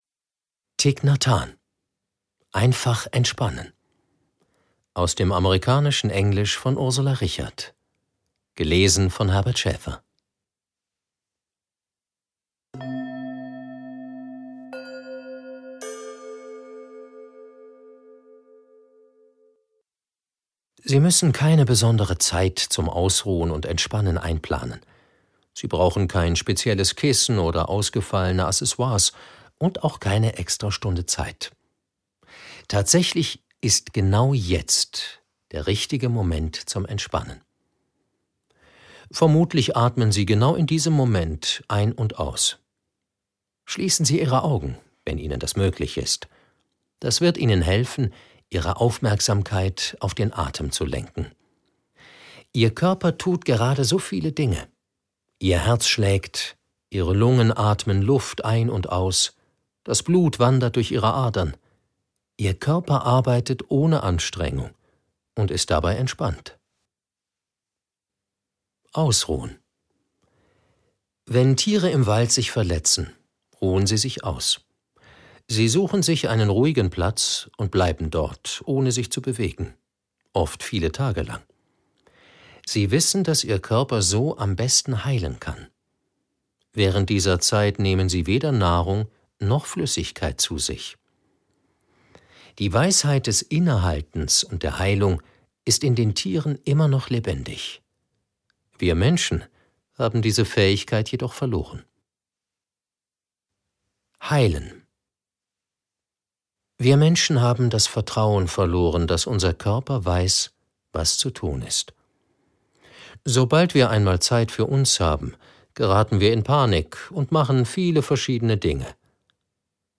2016 | 8. Auflage, Ungekürzte Ausgabe